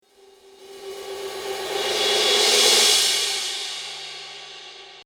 Cymbal swells � Superior Drummer 2.3 (Metal Machine)